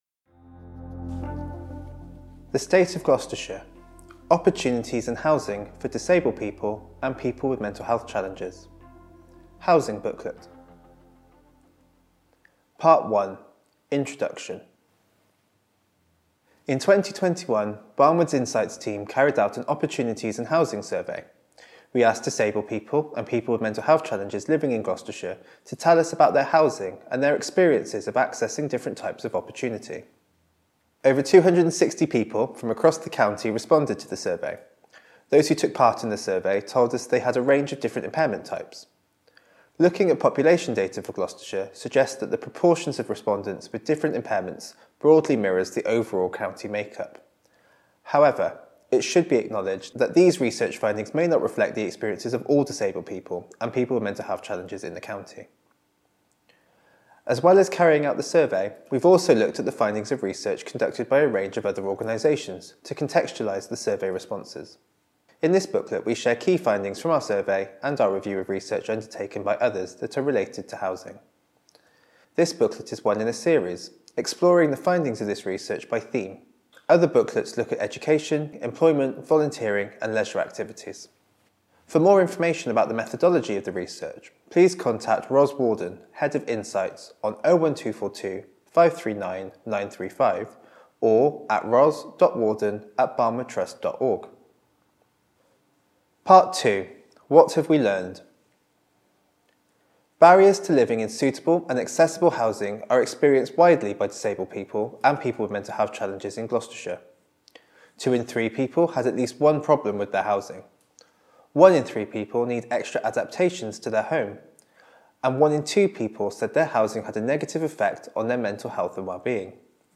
British Sign Language (BSL) translation Subtitled video of the report being read by one of our Researchers Audio recording of the report being read by one of our Researchers Easy Read version to read or download Large print version to read or download